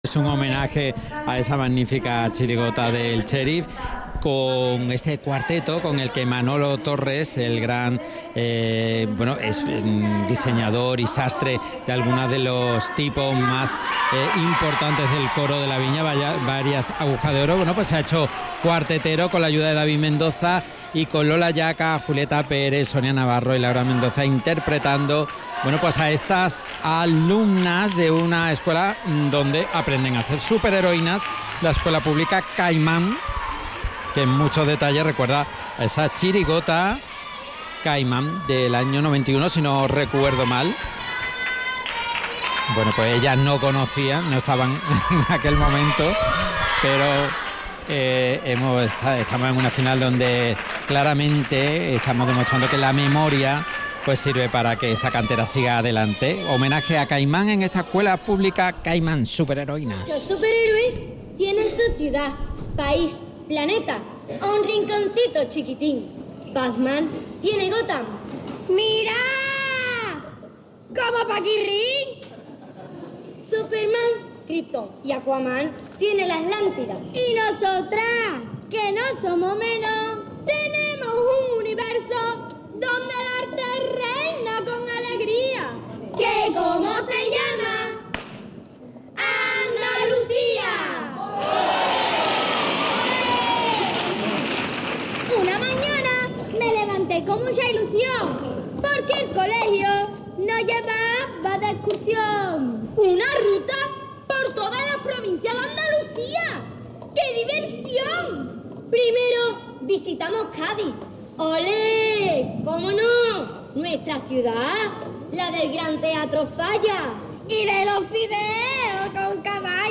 Cuarteto Infantil – Escuela pública Caimán Final